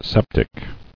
[sep·tic]